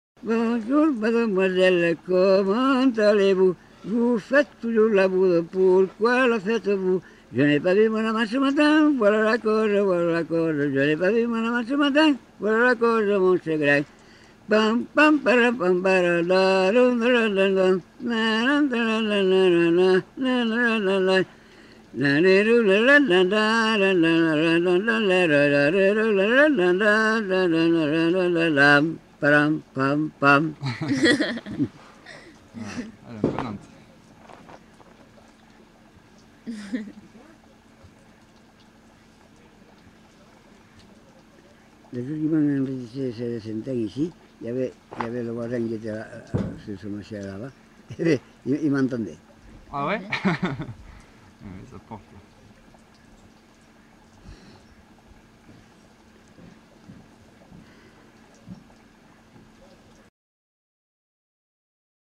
Aire culturelle : Couserans
Genre : chant
Effectif : 1
Type de voix : voix d'homme
Production du son : fredonné ; chanté
Danse : polka piquée